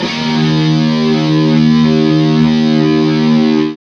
Index of /90_sSampleCDs/Roland L-CD701/GTR_Distorted 1/GTR_Power Chords